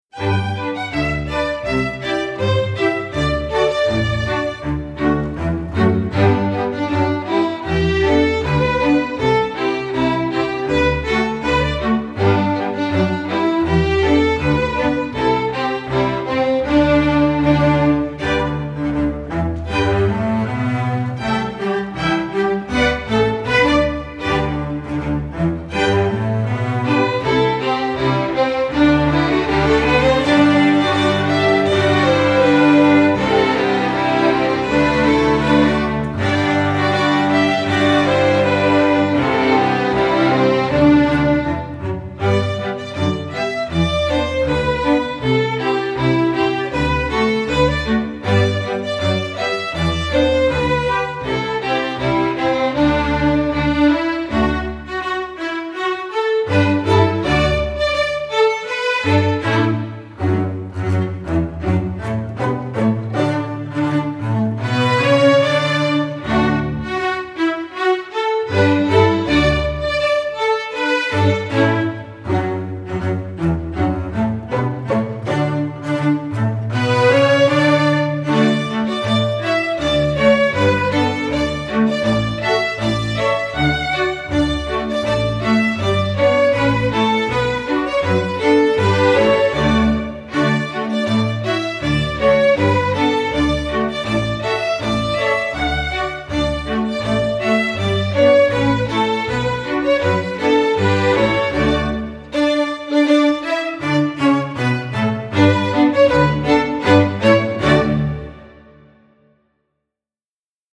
folk, traditional